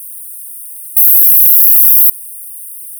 Цифровой маскиратор речи